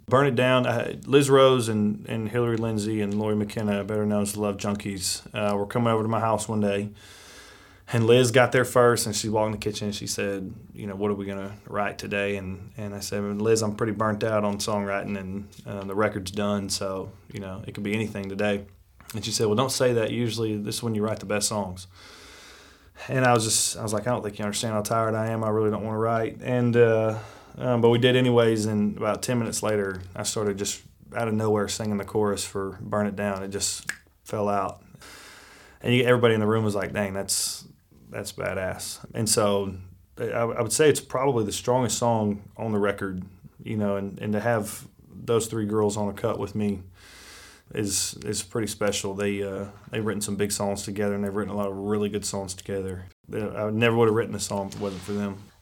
Parker McCollum talks about writing his new No. 1 single, "Burn It Down."